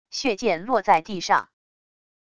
血剑落在地上wav音频